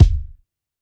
Havoc Kick 24.wav